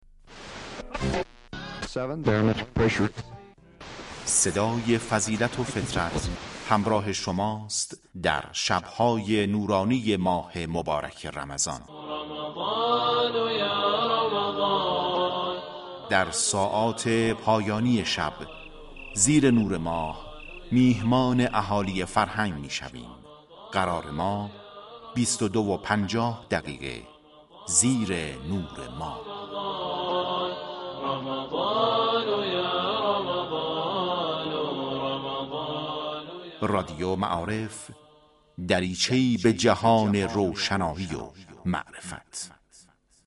این برنامه اختصاص دارد به گفتگوی صمیمی با افرادی كه در زندگی كارهای خاص و خارق العاده انجام داده اند و شنیدش برای مردم جذاب و الگوپذیر است انسانهای موفق در زمینه قرآن،‌مبارزان انقلابی ، دینی ، هنری، انفاق و ایثار و ....